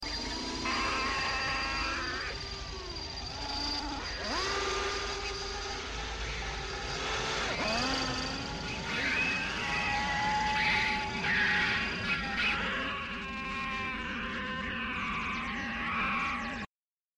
The Black Hole FX - Maximillian screams
The_Black_Hole_FX_-_Maximillian_screams.mp3